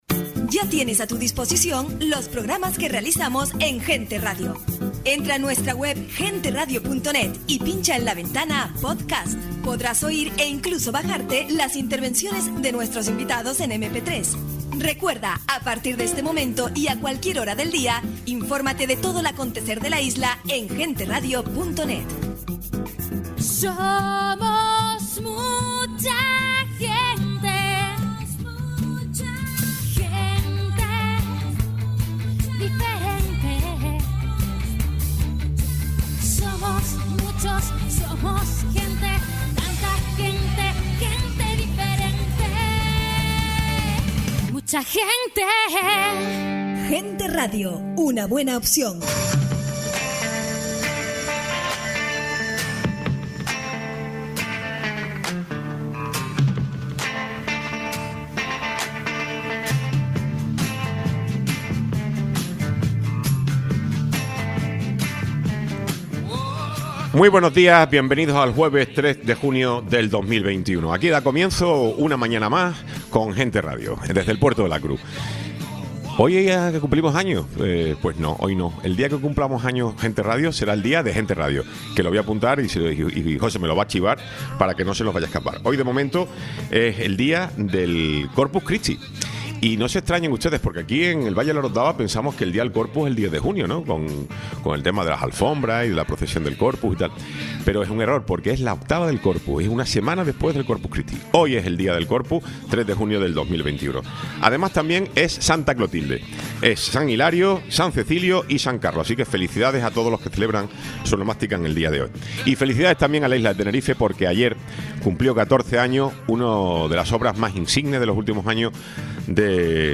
Tiempo de entrevisca con David Hdez, concejal de Ciudad Sostenible y Planificación